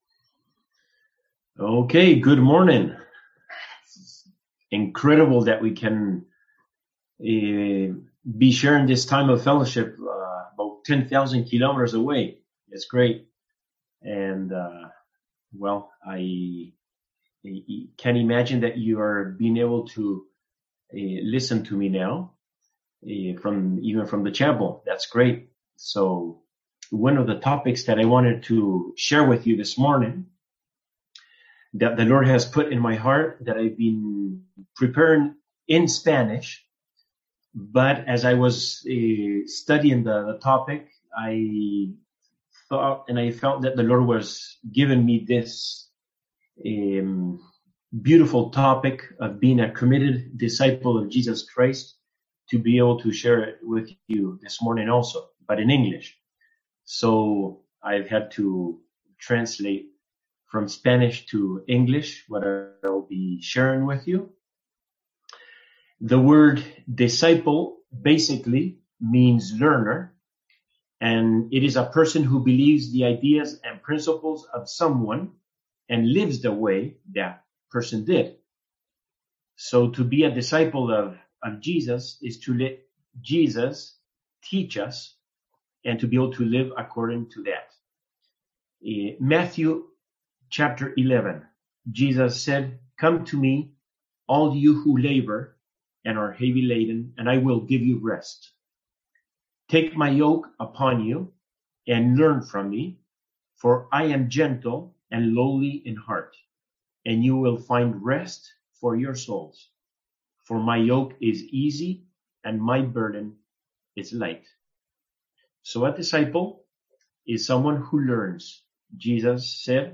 Passage: Luke 14:25-35 Service Type: Sunday AM Topics: Discipleship